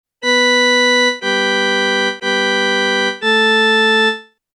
If we take James of Hesbaye’s example in the dorian mode, it would mean changing this diatonic interval relationship …